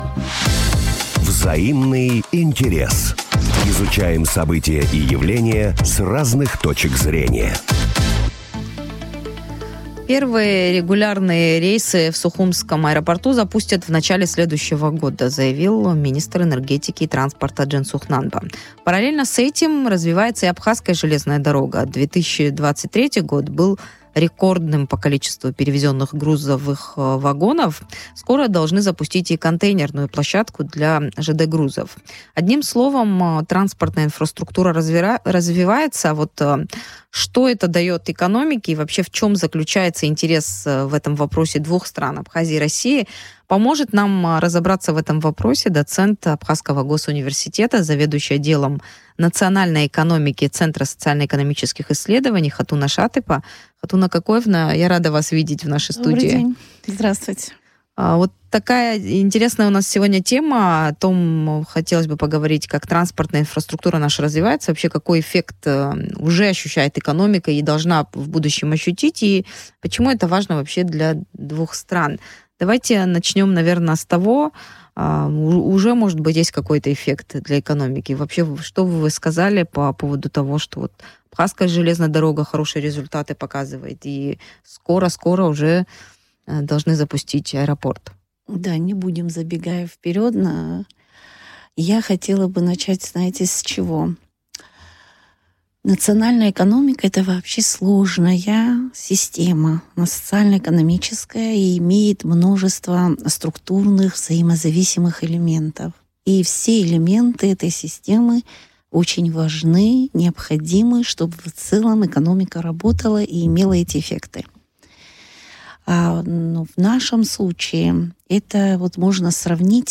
в эфире радио Sputnik